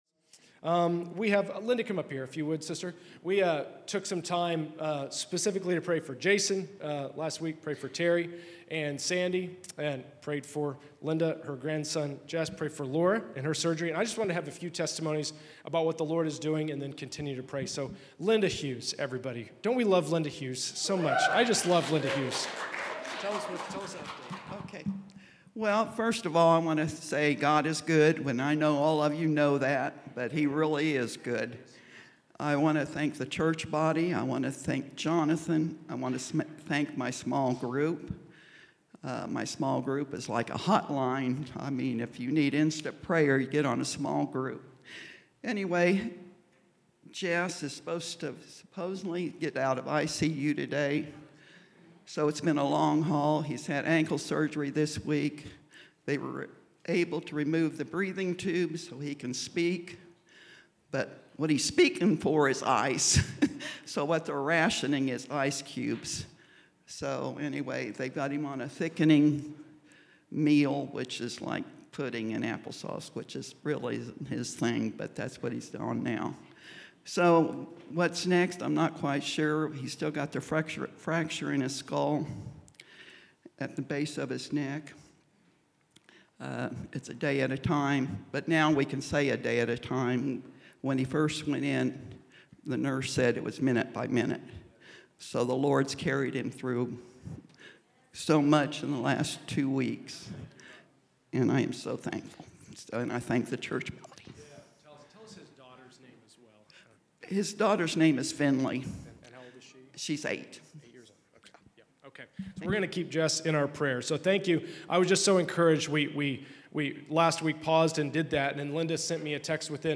Testimonies of Healing & Thanksgiving